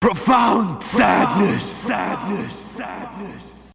profound_sadness
profound_sadness.mp3